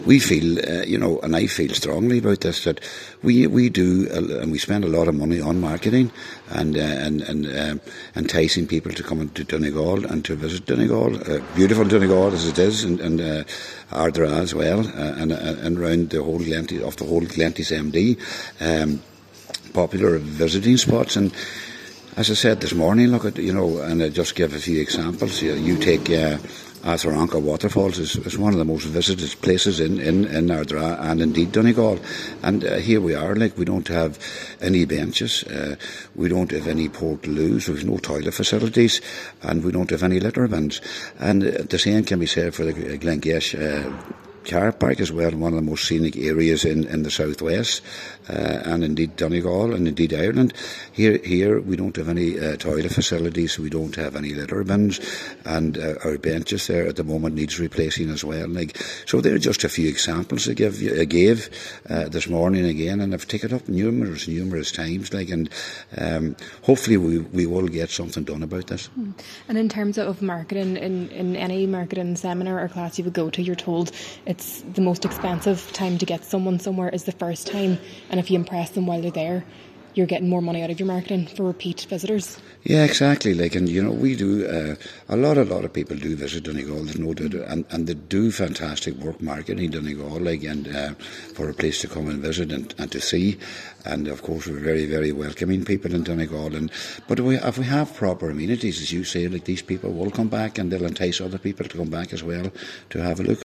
Cllr. Anthony Molloy says facilities such as toilets and benches should be available in areas such as Ardara which attracts many visitors.